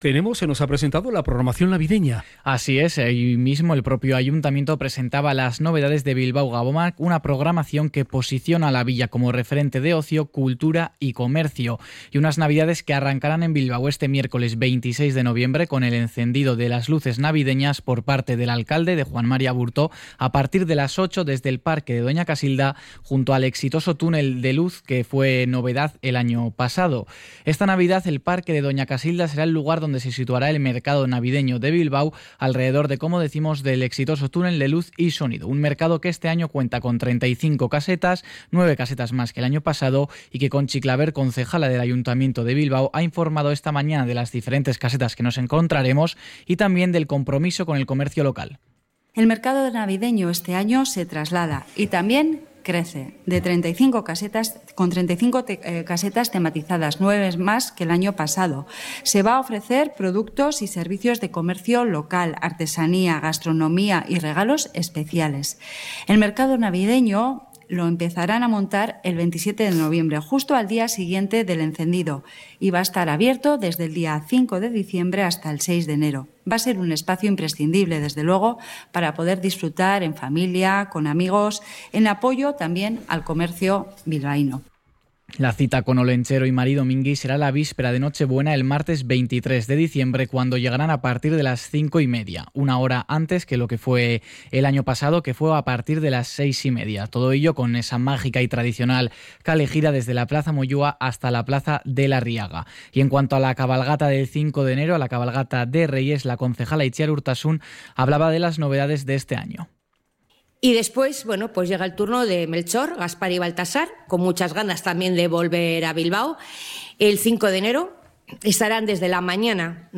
CRONICA-NAVDAD.mp3